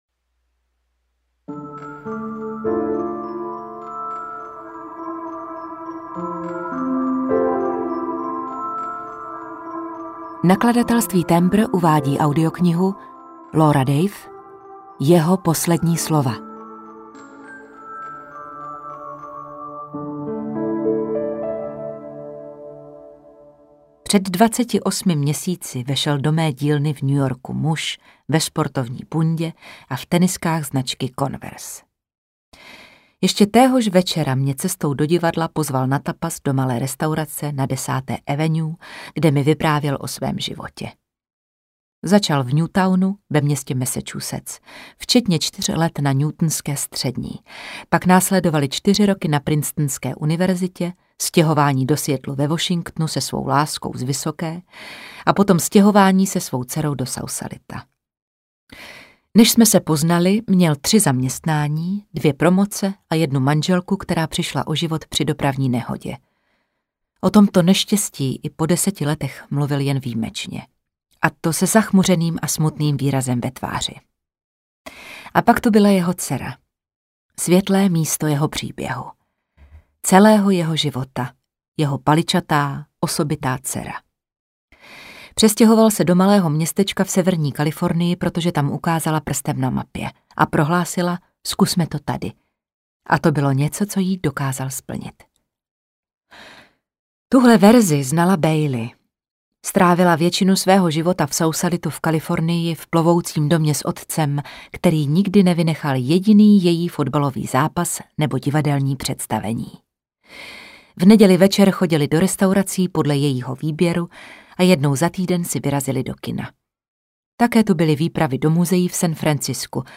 Jeho poslední slova audiokniha
Ukázka z knihy